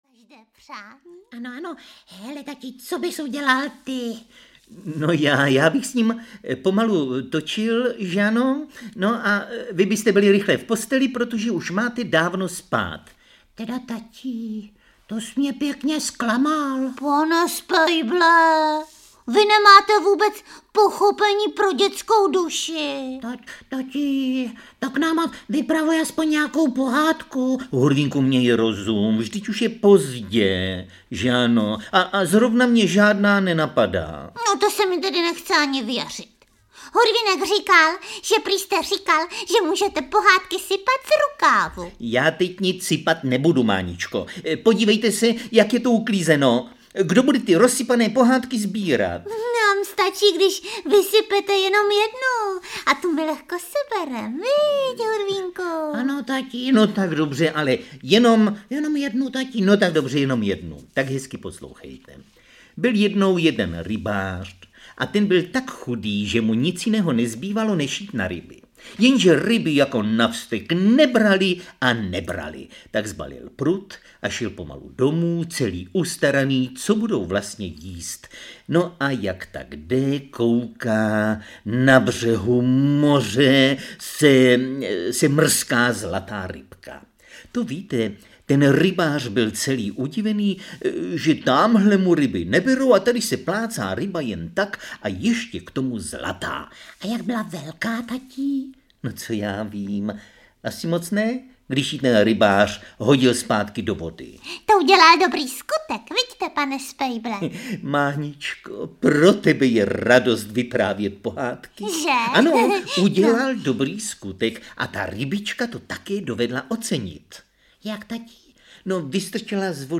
Hurvínkovy příhody 4 audiokniha
Ukázka z knihy
• InterpretMiloš Kirschner, Helena Štáchová